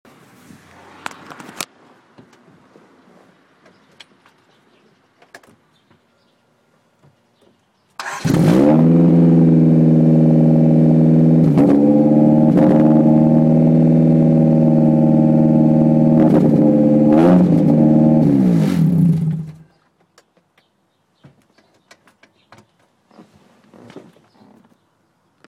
Fahrzeug: CRX ED9
also der sound ist wirklich schön brummig und wirklich laut...allerdings nur im standgas, bin echt am überlegen mal so ne runde zu drehen :o